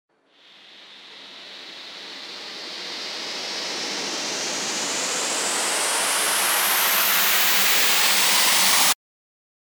FX-1537-RISER
FX-1537-RISER.mp3